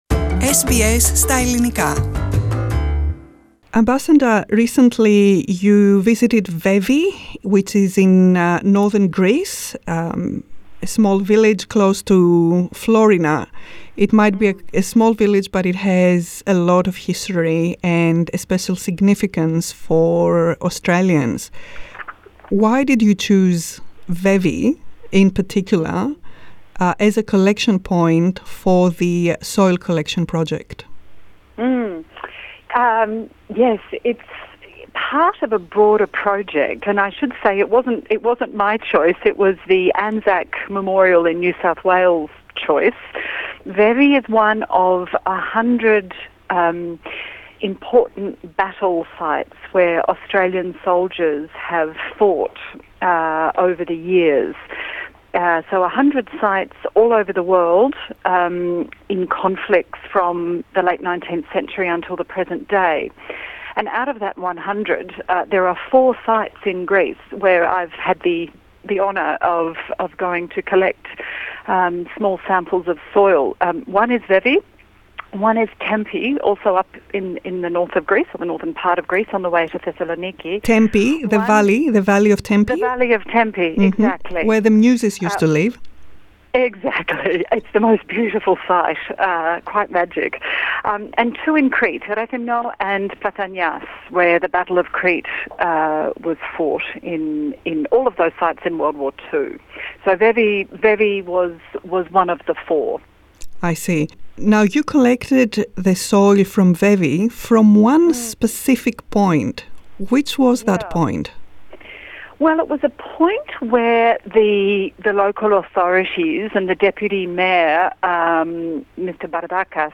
Australia's Ambassador to Greece Kate Logan has collected soil from four sites in mainland Greece and Crete where Australian troops fought in WW2. The soil will be sent to Sydney to be placed at a special site together with soil from other Australian battlegrounds as part of the commemorations for the ANZAC Centenary. Ambassador Logan spoke to SBS Greek, about the Soil Collection Project, Greece and the ANZAC connection.